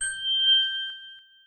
Case Location Bell.wav